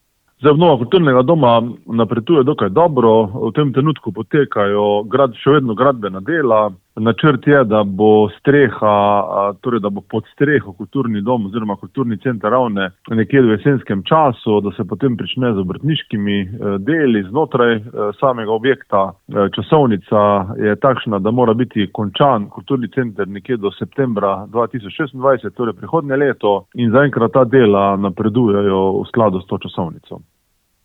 Župan Občine Ravne na Koroškem Tomaž Rožen:
IZJAVA ROZEN KULTURNI DOM.mp3